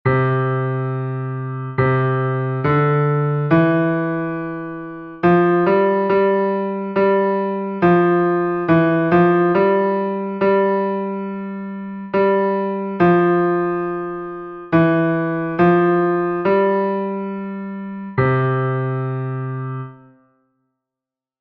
Escoitando melodías en clave de Fa
Para iso, comezaremos con ditados a unha voz en clave de Fa, centrándonos nos graos tonais aos que lle engadiremos notas por graos conxuntos.
Melodía 1  : Do M                            Melodía 2 : Fa M